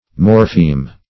morpheme \mor"pheme\ (m[^o]r"f[=e]m), n. (Linguistics)